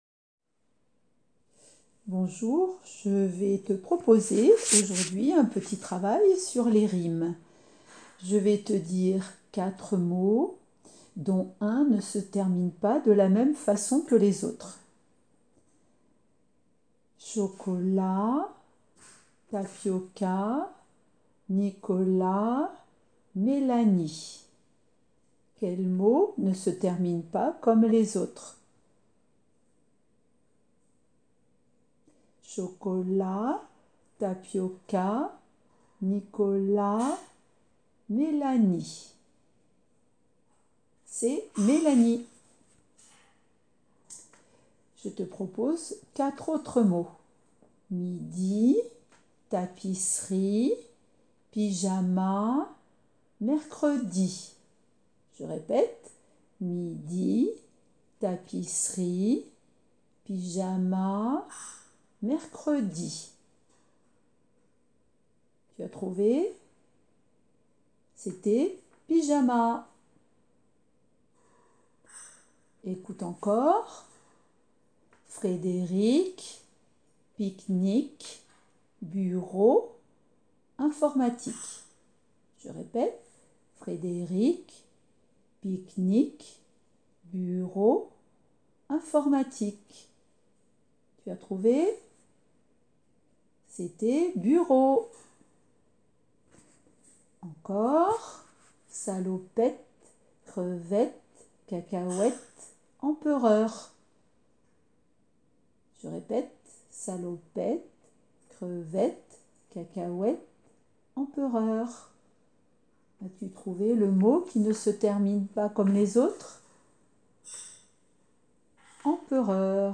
En cliquant sur le petit triangle ci-dessous, tu vas entendre des séries de mots.